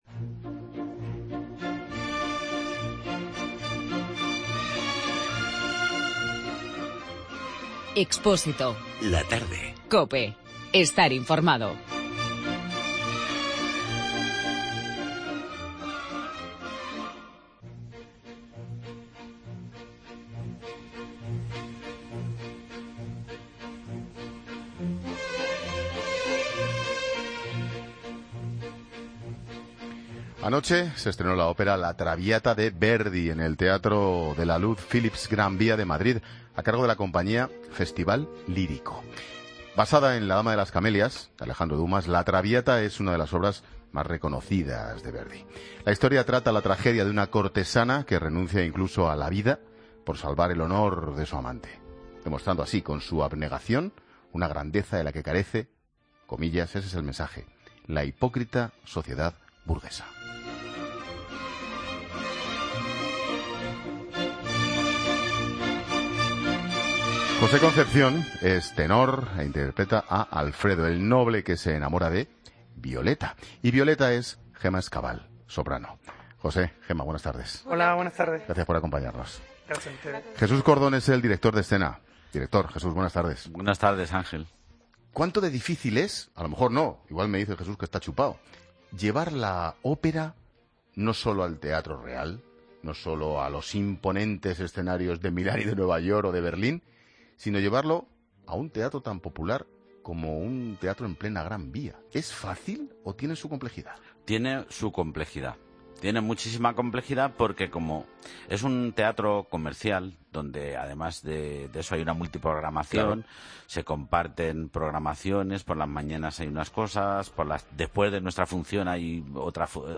Escucha a la soprano
y al tenor